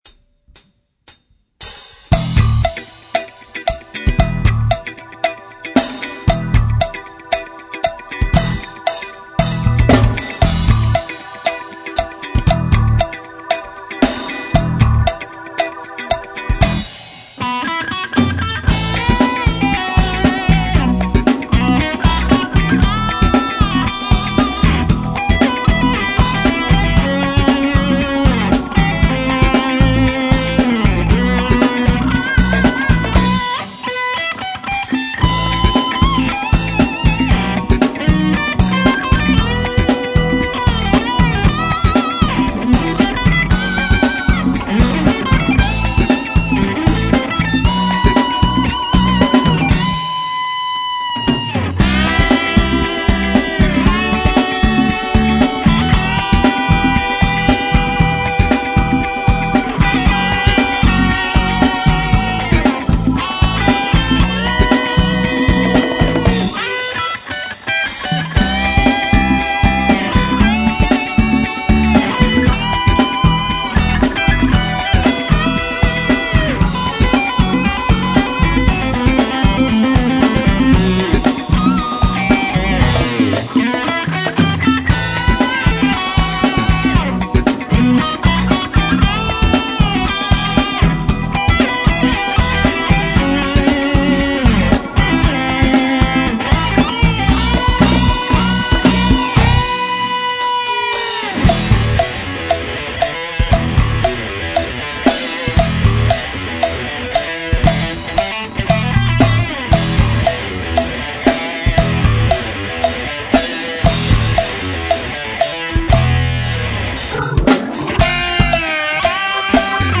БЛЮЗ-РОК